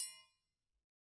Triangle6-HitM_v2_rr2_Sum.wav